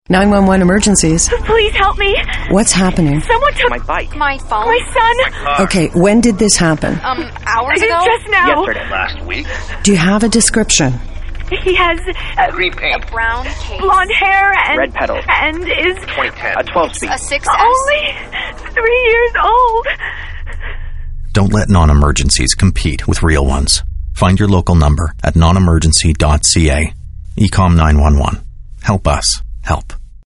SilverPublic Service - Radio Campaign